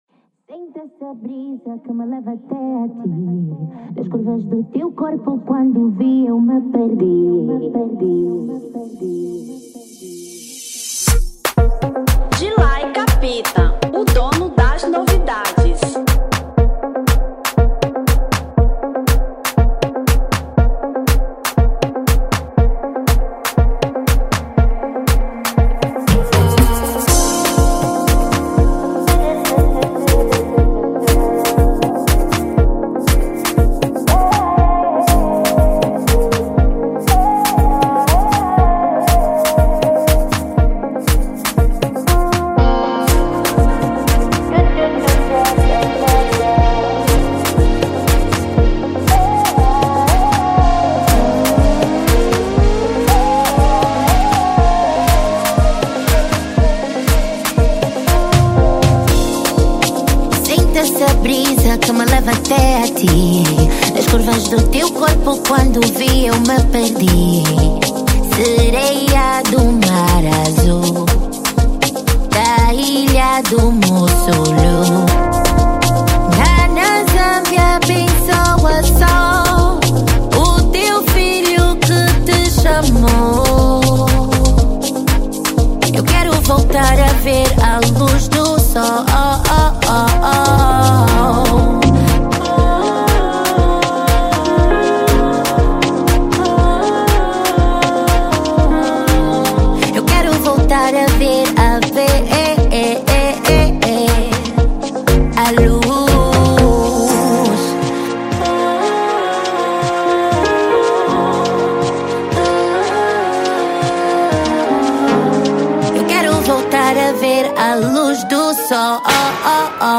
Afro Beat 2025